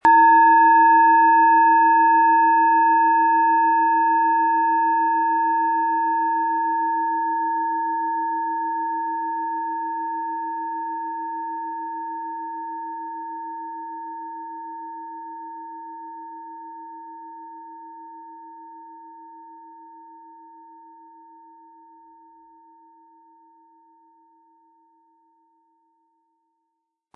• Mittlerer Ton: Venus
• Höchster Ton: Mond
PlanetentöneAlphawelle & Venus & Mond (Höchster Ton)
MaterialBronze